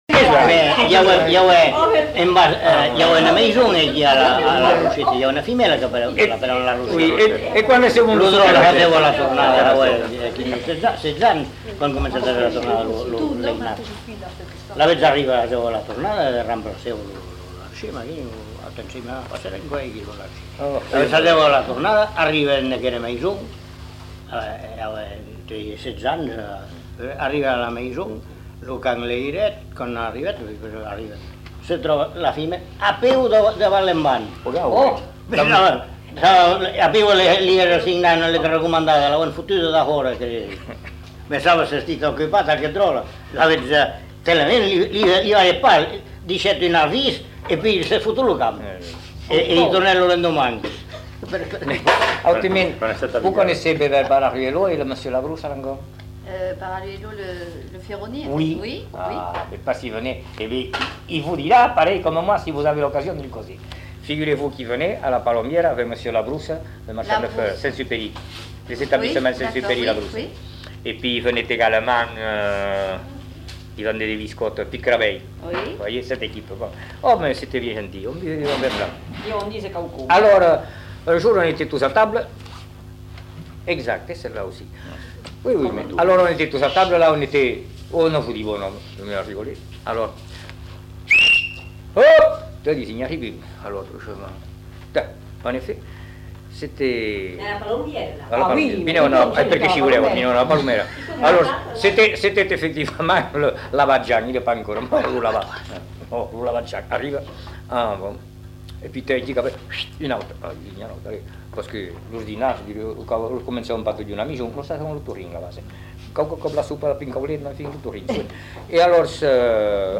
Genre : témoignage thématique
Contenu dans [enquêtes sonores]